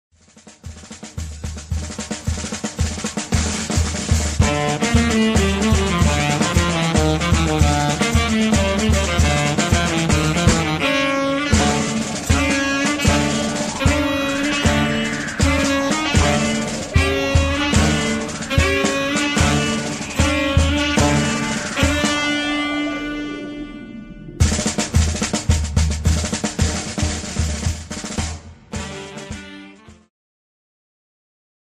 Segment Jazz-Rock
Progressive